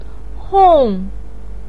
hong4.mp3